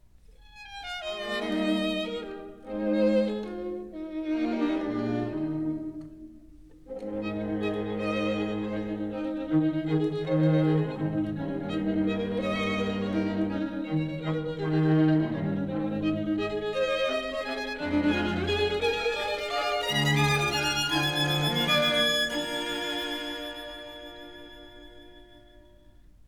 violin
viola
cello
Studios,30th Street, New York City